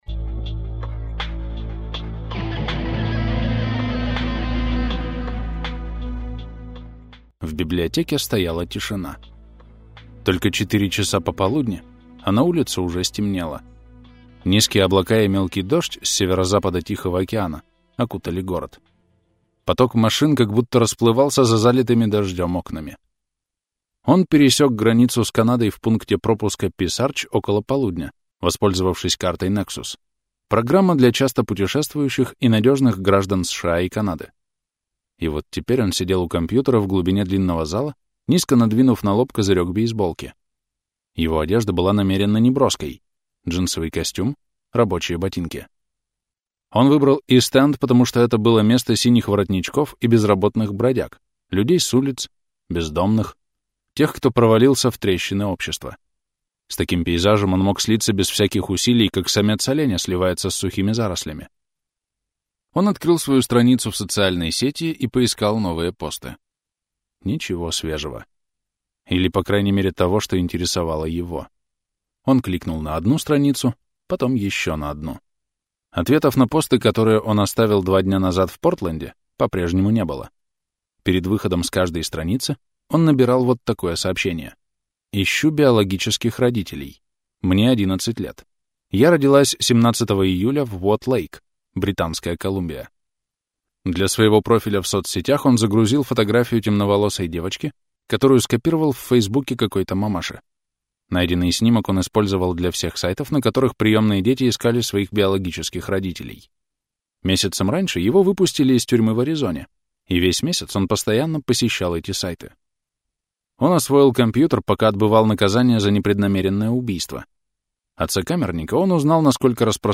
Аудиокнига Приманка для моего убийцы | Библиотека аудиокниг